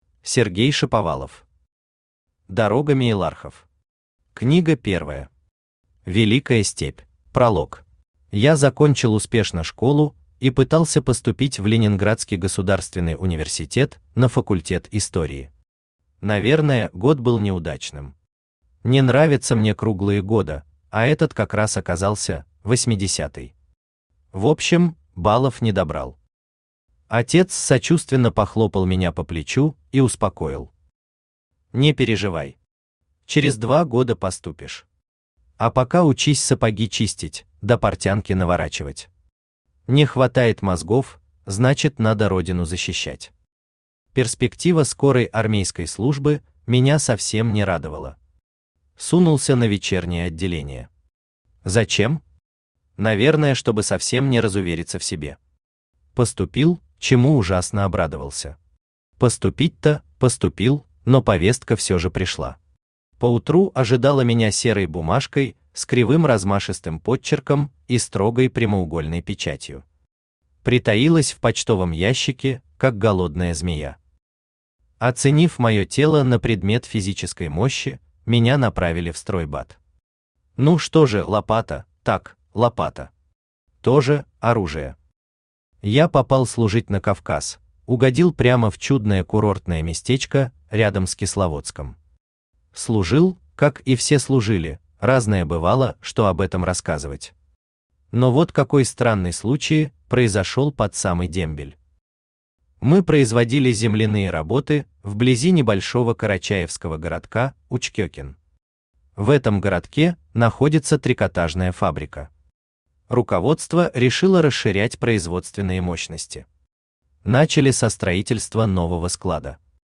Великая степь Автор Сергей Анатольевич Шаповалов Читает аудиокнигу Авточтец ЛитРес.